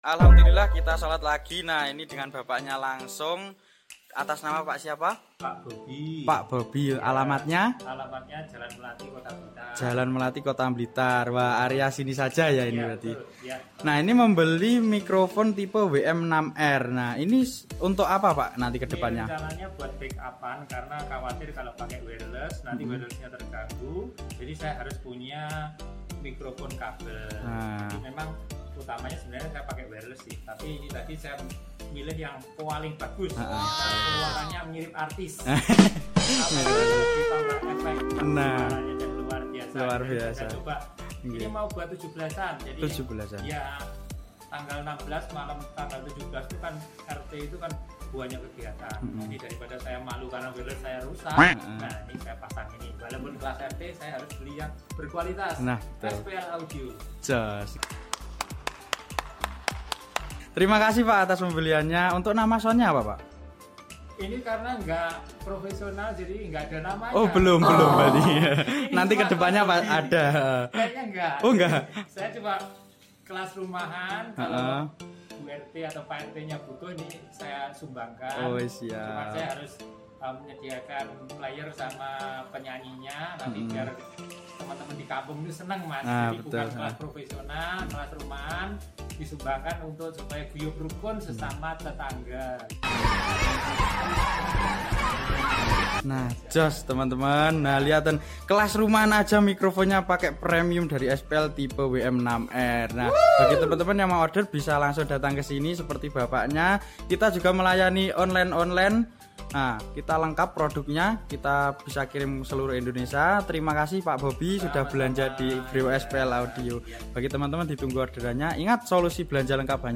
SUARA NYA JERNIH JOSJISS BANGET POKOK🔥